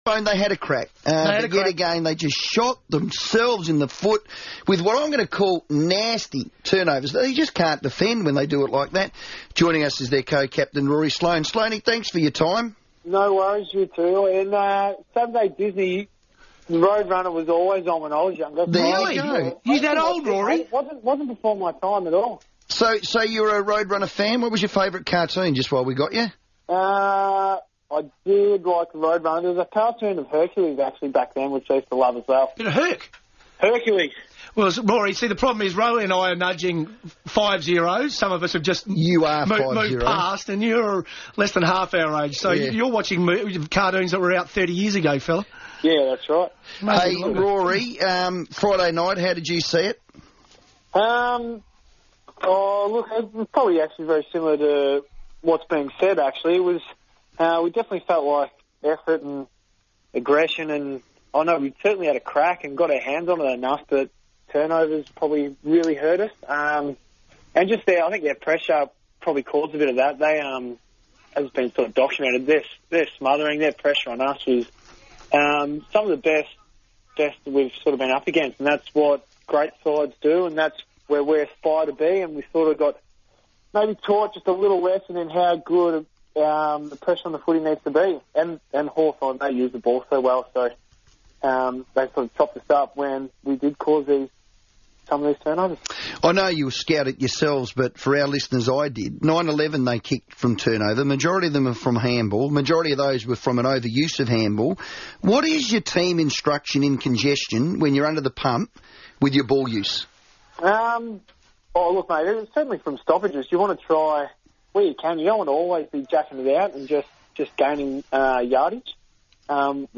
Rory Sloane joined the FIVEaa Sports Show and said Friday night's loss to reigning premiers Hawthorn exposed some areas for improvement for the playing group